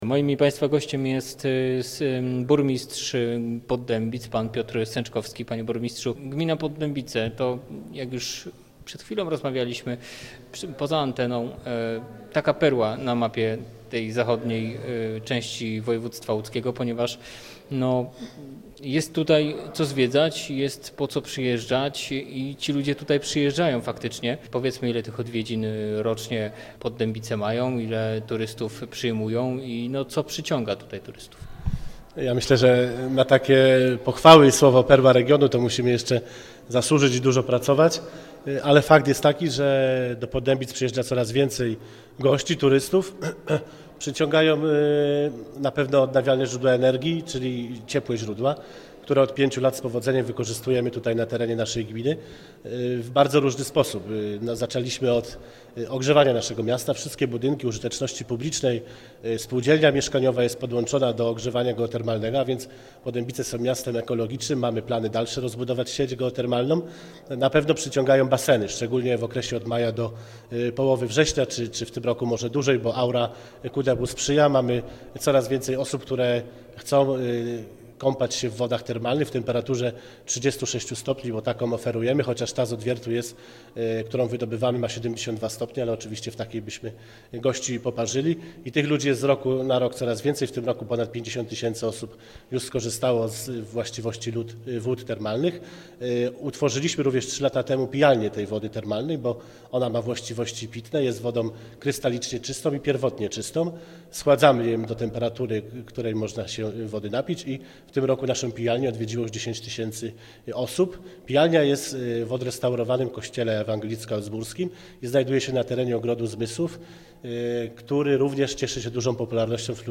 Gościem Radia Łódź Nad Wartą był dzisiaj burmistrz Poddębic, Piotr Sęczkowski.